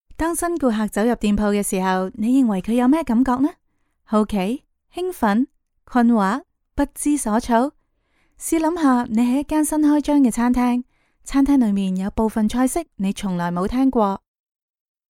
Female
My voice is warm, friendly, clear, gentle, enthusiastic, firm, and sweet, with the versatility to perform across e‑learning, narration, commercial work, character voice acting, and more.
Explainer Videos